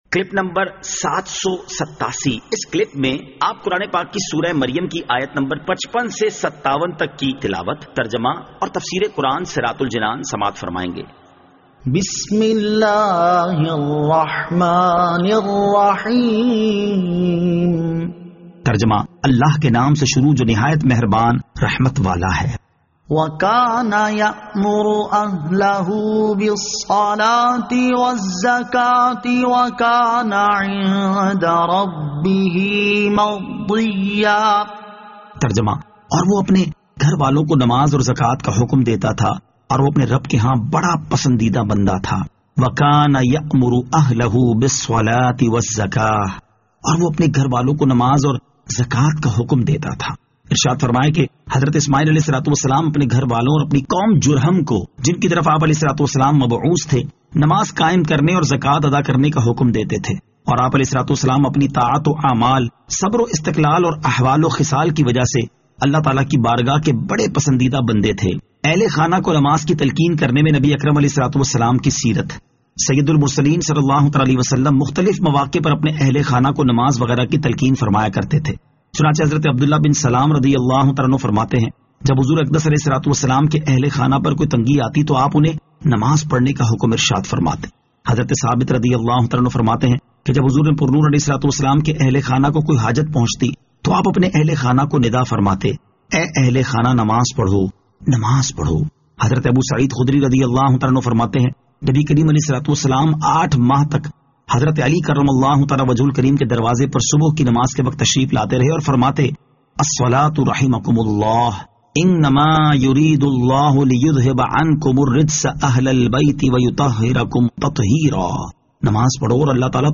Surah Maryam Ayat 55 To 57 Tilawat , Tarjama , Tafseer